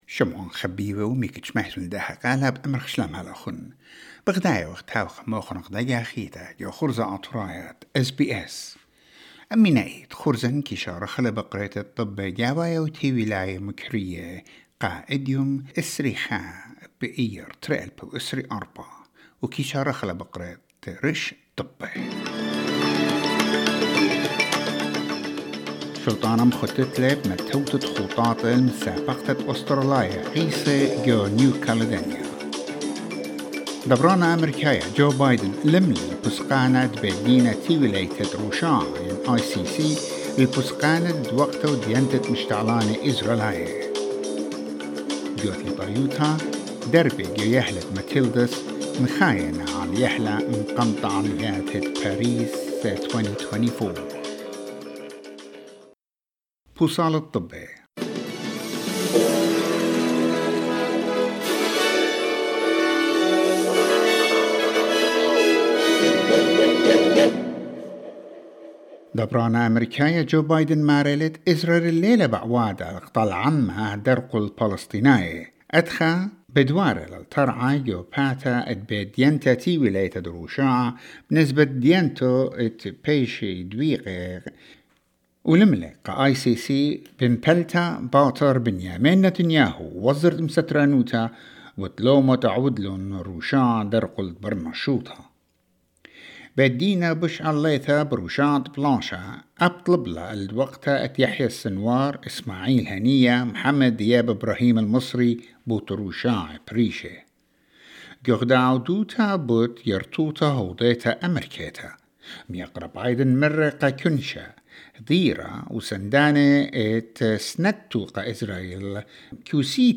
SBS Assyrian news bulletin: 21 May 2024